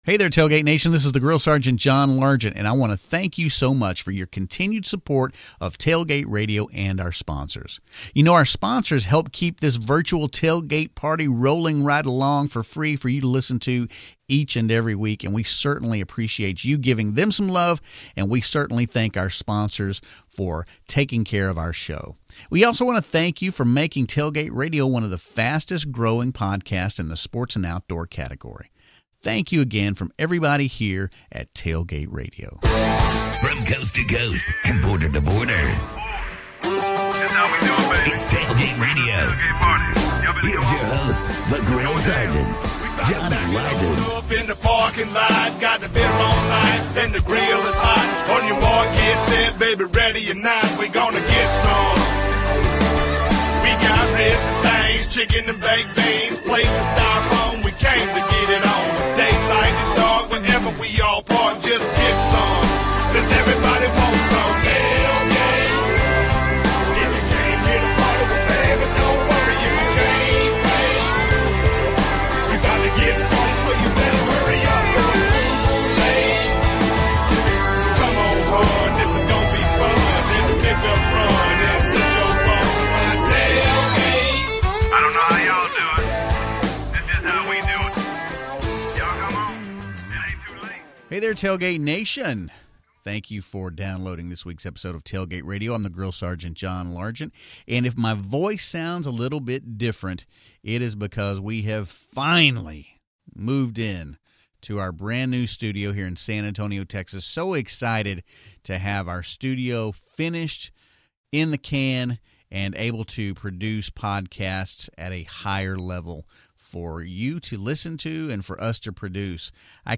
Tailgate Radio comes to you from Sin City, Las Vegas, Nevada as we talk to innovators in the world of tailgating products and accessories including: